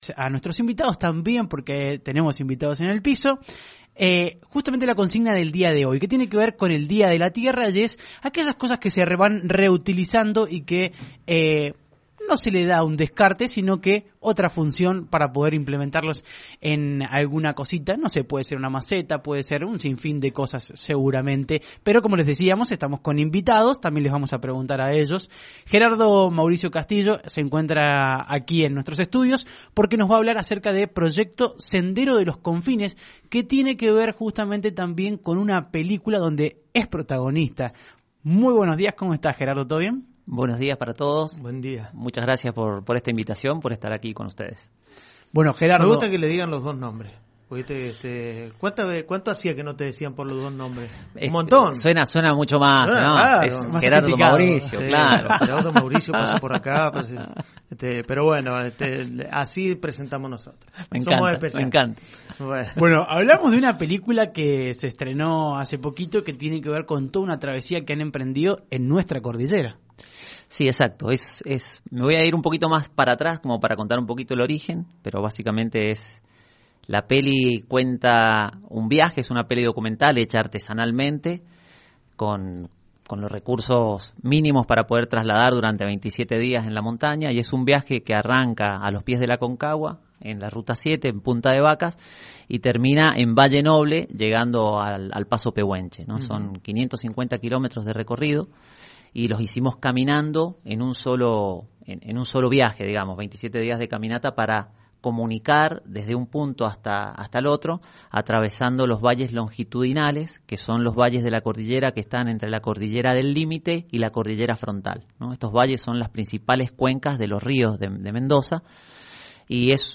En los estudios de LV18